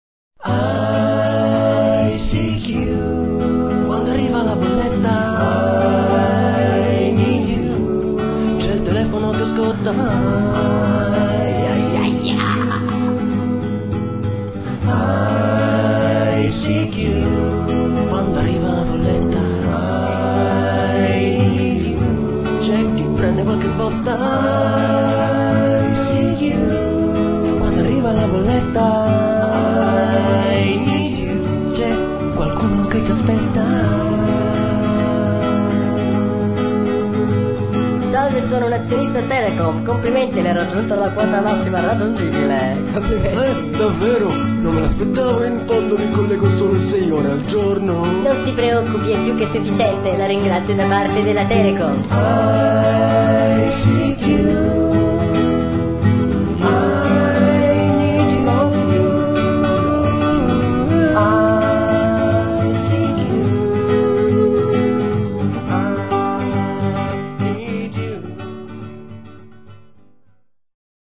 ICQ i need You !!! il delirio pseudo-musical-trash dedicato a ICQ !!
Legenda: TESTO viola => coro
TESTO nero => voce singola